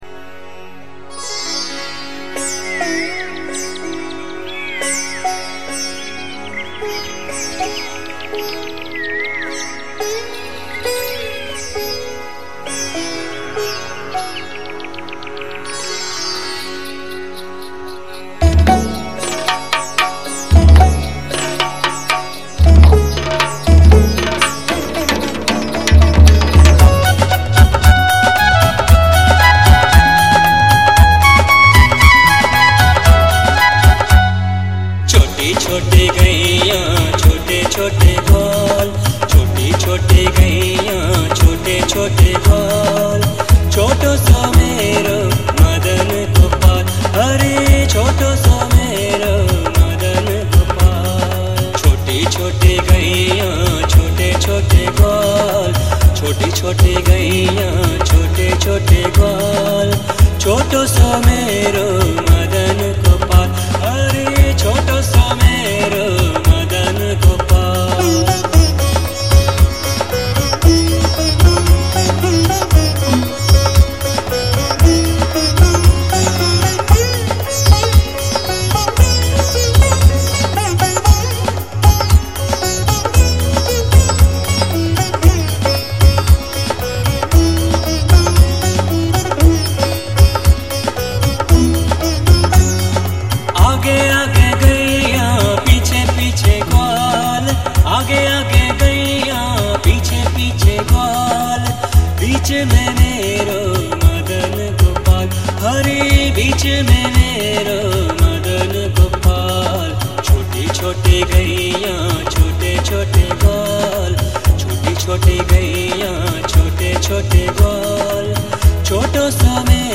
An Authentic Ever Green Sindhi Song Collection